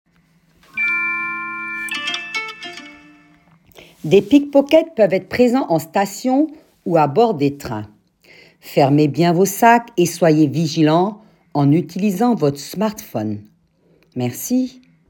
Annonce sncf
Voix off